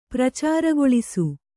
♪ pracāragoḷisu